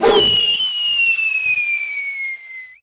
handgrenade_fire01.wav